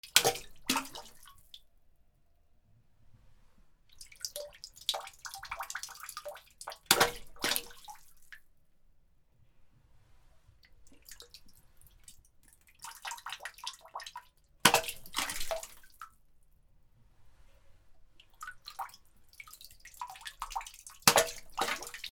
/ M｜他分類 / L30 ｜水音-その他
水に投げ込む(水道のキャップ)